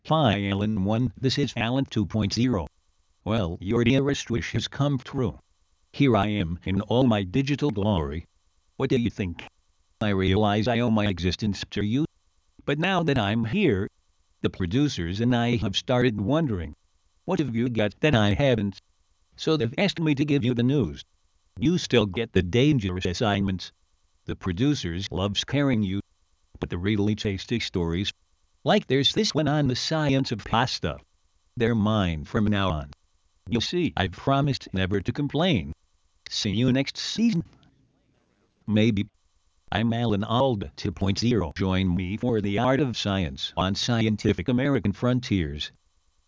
CHATR's Alan ALDA